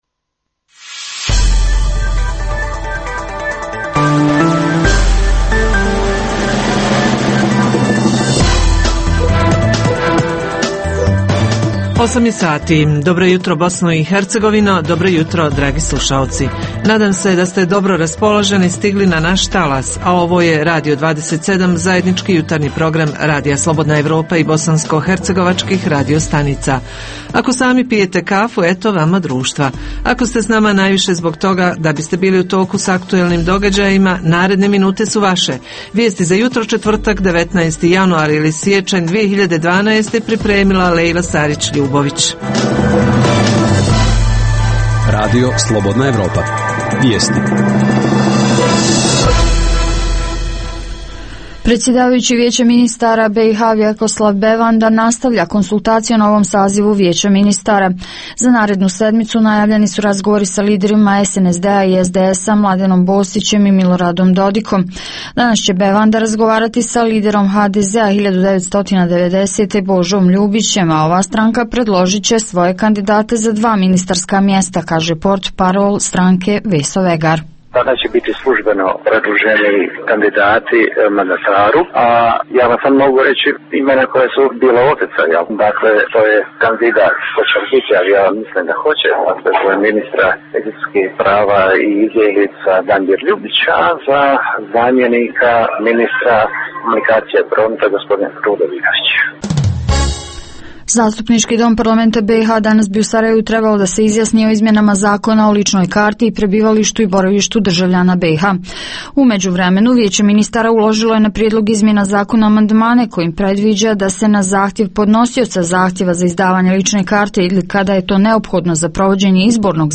Jutarnji program traži odgovor na pitanje kakva je situacija sa snježnim padavinama u BiH, ima li sela koja su zametena i iz kojih je stanovnicima otežan odlazak u Dom zdravlja, u prodavnice, i za koje je problematično svakodnevno obavljanje radnih ili uopšte, životnih aktivnosti. Javljaju se reporteri iz Srebrenice, Banjaluke, Travnika, Doboj - Juga i Jablanice.
Redovni sadržaji jutarnjeg programa za BiH su i vijesti i muzika.